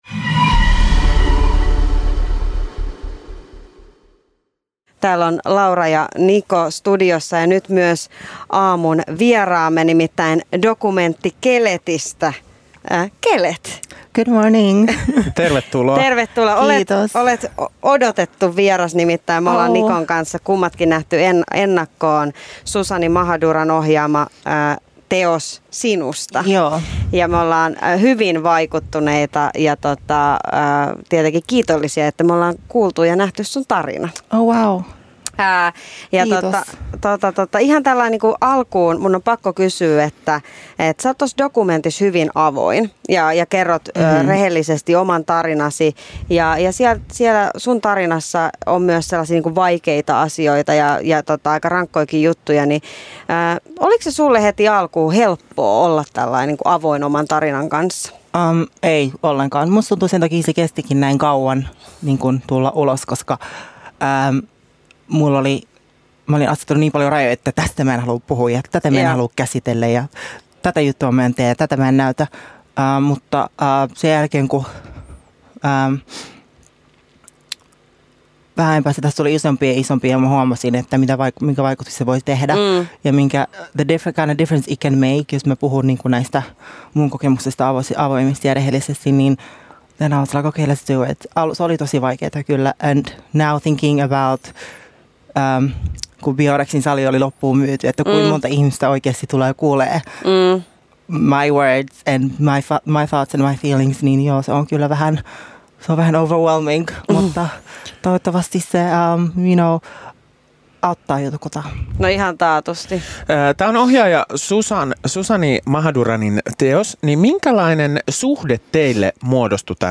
Haastattelu